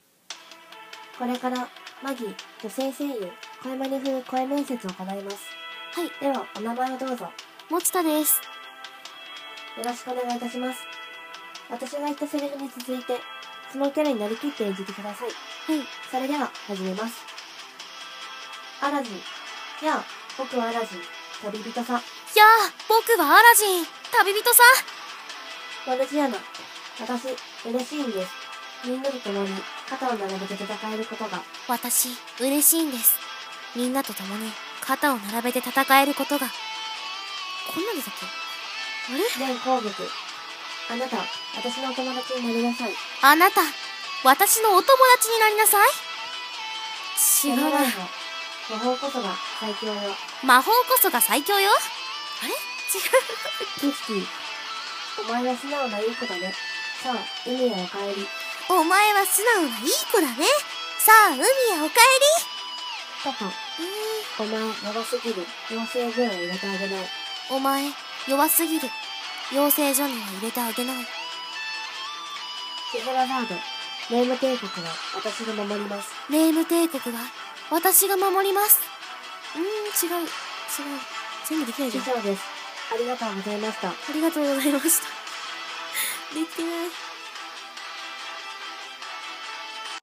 マギ 女性声優 声真似風声面接